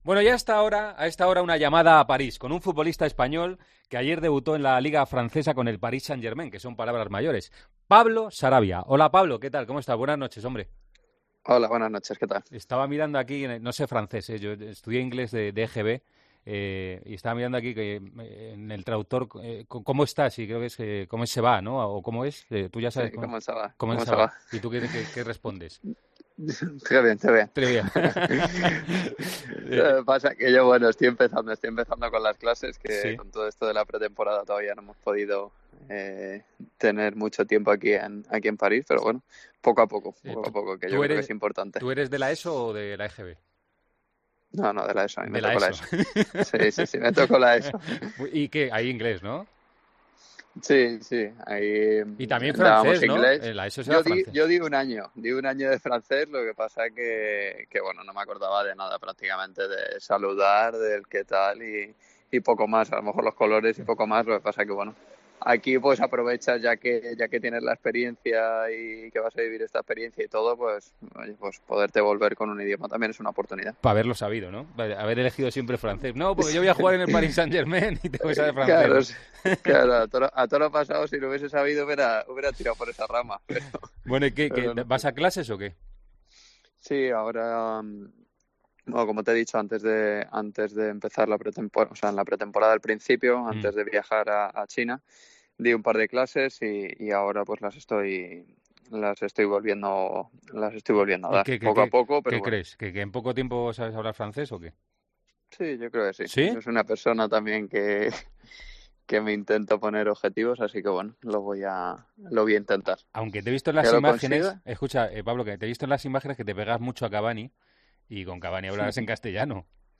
El jugador del PSG pasó por los micrófonos de 'El Partidazo de COPE' tras su debut este domingo en la Ligue 1 como titular y con triunfo ante el Nimes.